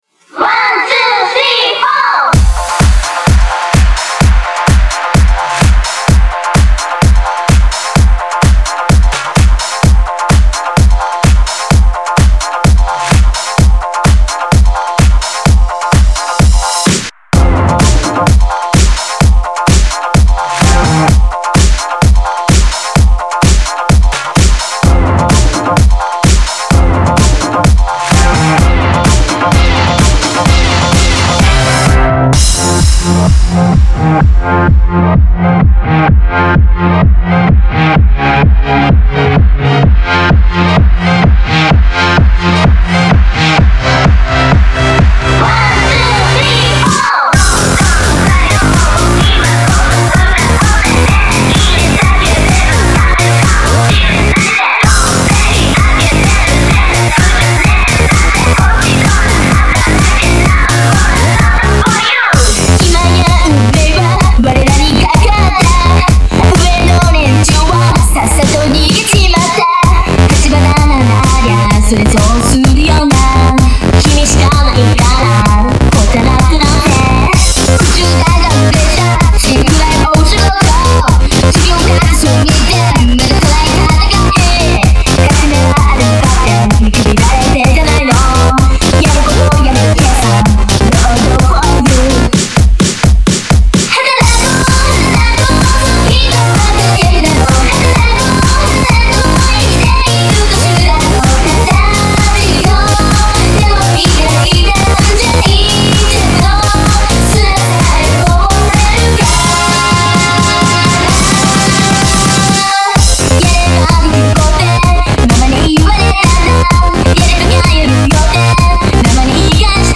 Genre(s): Electro-House